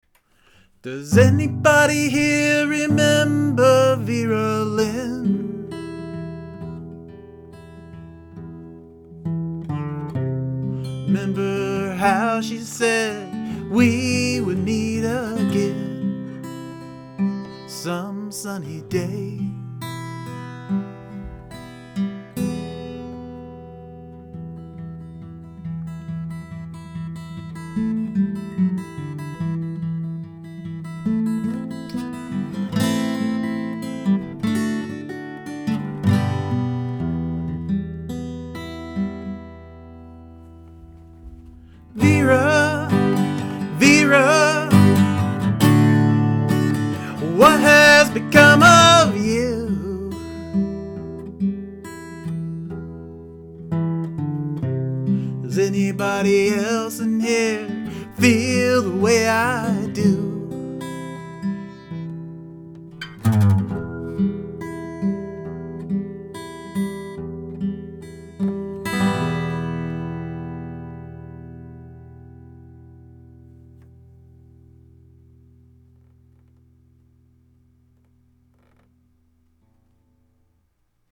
Room recording on a condenser mic into the br-900.
This is great - Excellent vox & acoustic.